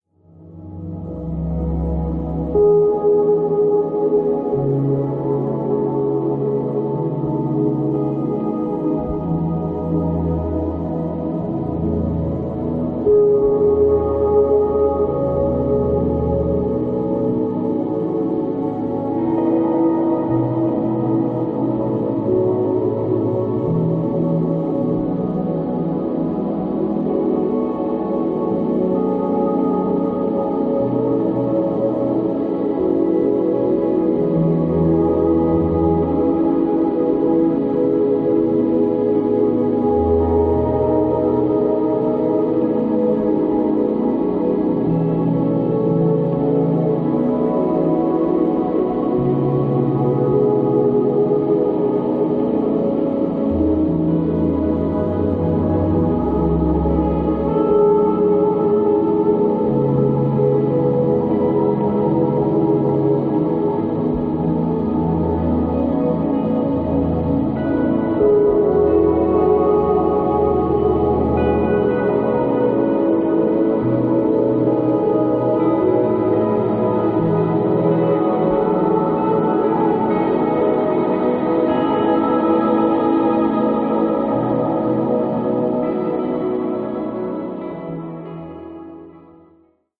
飛鳥地方への旅と「石舞台古墳」からインスピレーションを得て制作されたという、古代への追想をめぐらす迷宮的アンビエント。